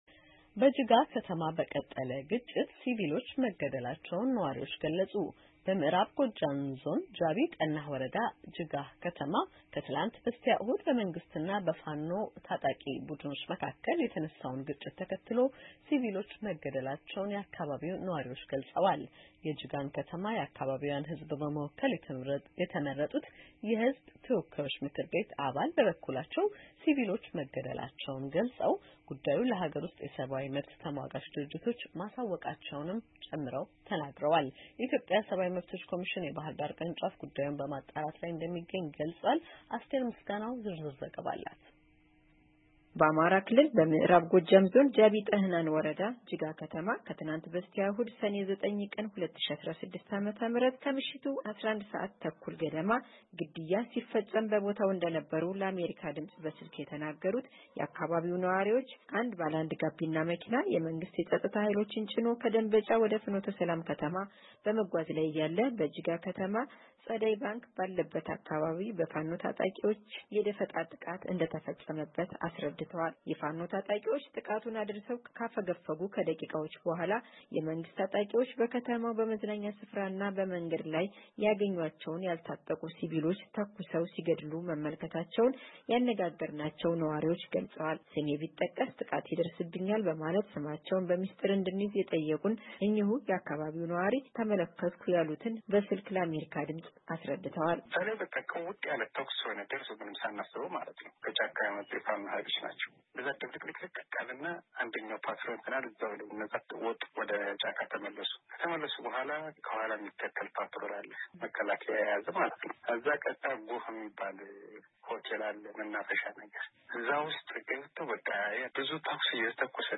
የአካባቢውን ነዋሪዎች እና የሰብአዊ መብት ኮሚሽን አስተያየት ጠይቃ ያጠናቀረችውን ዘገባ እንደሚከተለው ታቀርበዋለች።